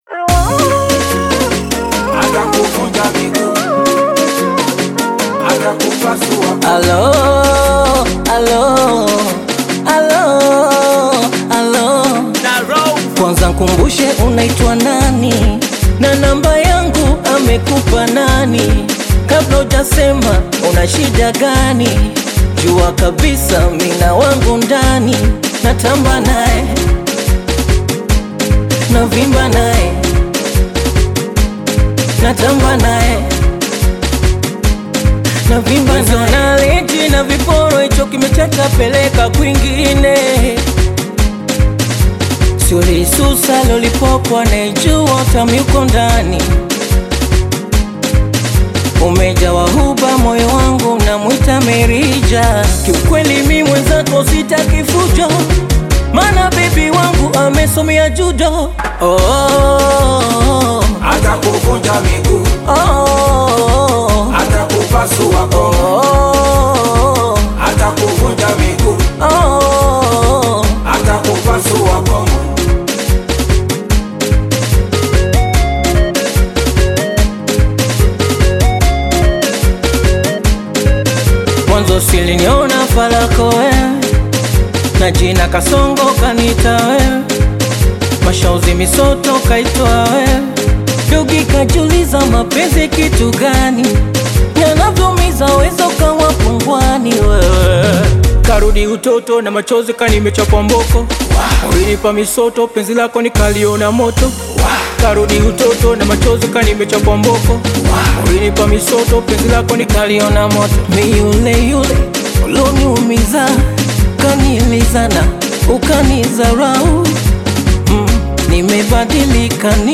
smooth Bongo Flava/Afro-Pop single
warm vocals and melodic hooks over polished production
With its expressive delivery and catchy rhythm